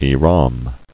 [ih·ram]